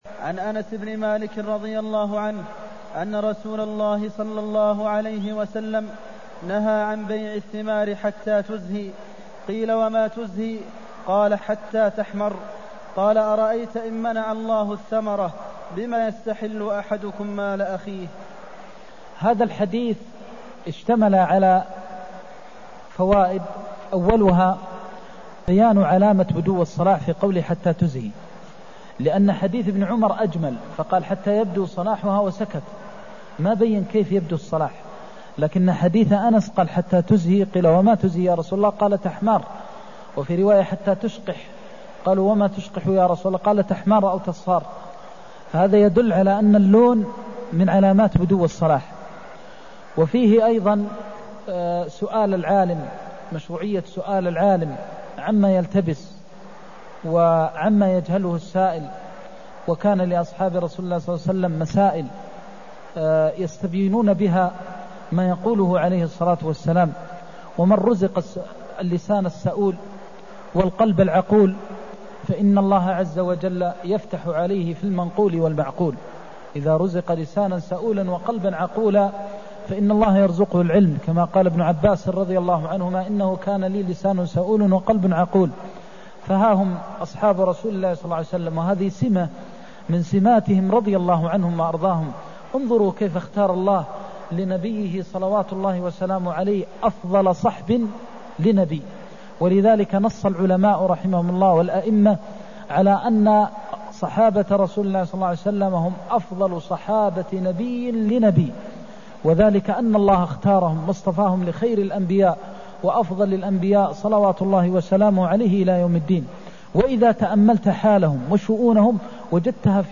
المكان: المسجد النبوي الشيخ: فضيلة الشيخ د. محمد بن محمد المختار فضيلة الشيخ د. محمد بن محمد المختار نهيه عن بيع الثمار حتى تزهي (248)